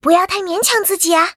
文件 文件历史 文件用途 全域文件用途 Fifi_fw_02.ogg （Ogg Vorbis声音文件，长度1.4秒，115 kbps，文件大小：19 KB） 源地址:游戏语音 文件历史 点击某个日期/时间查看对应时刻的文件。